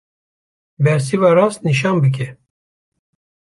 Pronunciato come (IPA)
/rɑːst/